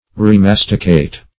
Search Result for " remasticate" : The Collaborative International Dictionary of English v.0.48: Remasticate \Re*mas"ti*cate\ (r?-m?s"t?-k?t), v. t. To chew or masticate again; to chew over and over, as the cud.